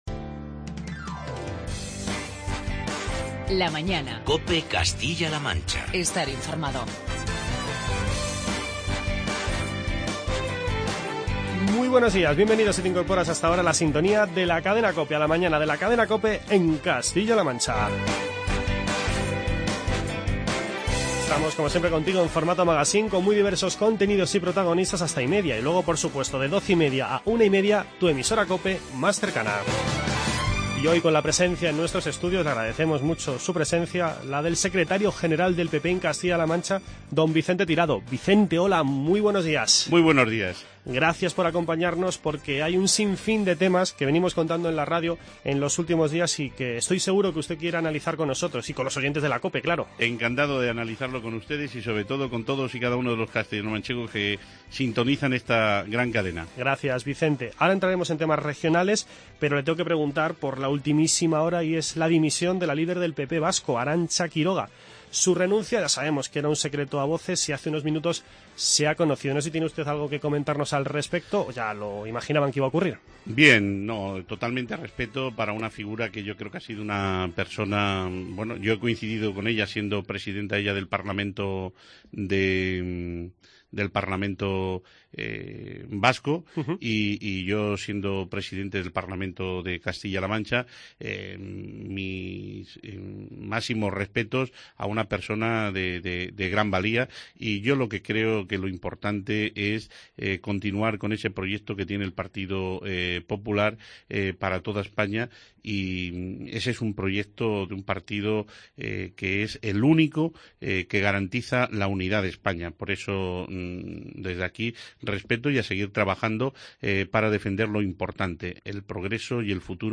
Charlamos con el secretario general del PP de Castilla-La Mancha, Vicente Tirado.